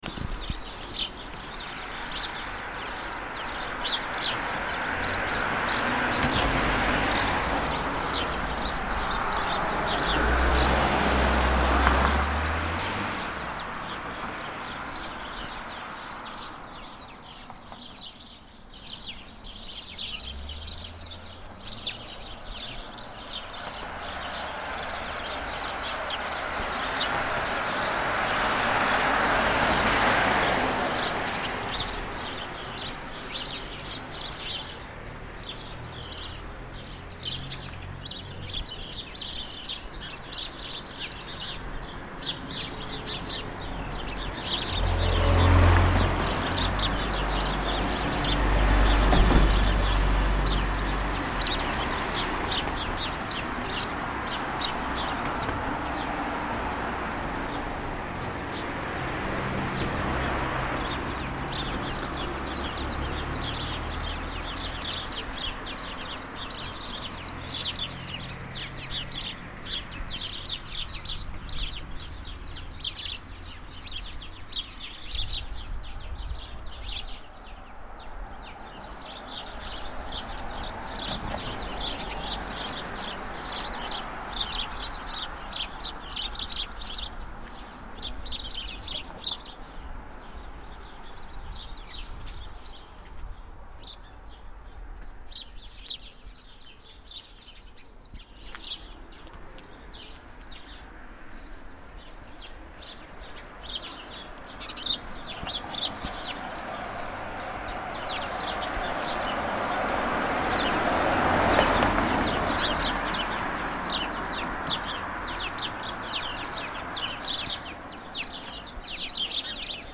Hedge sparrows at the roadside
Sparrows chattering in a garden hedge at the side of the road with passing traffic and an occasional person. Recorded in Maltby, UK